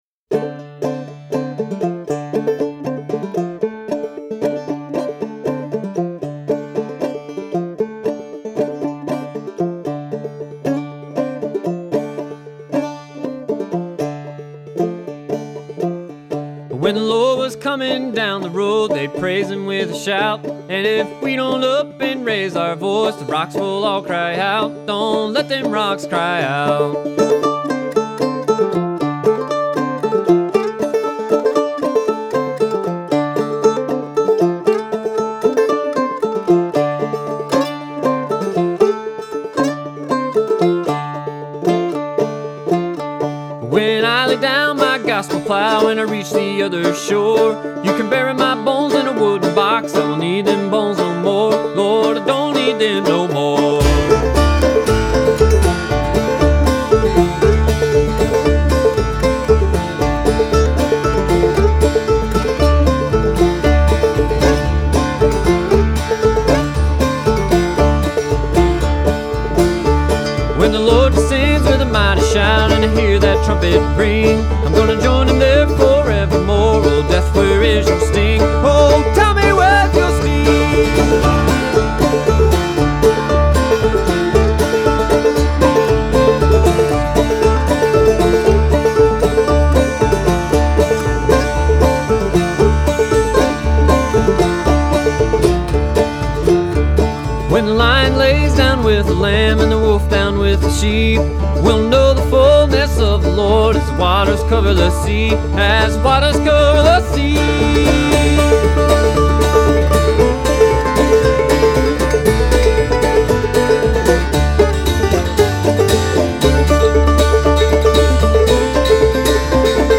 a bluegrass band from Nashville
who also does vocals and banjo for it.
mandolin
guitar
bass
the instrumental bridge